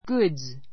ɡúdz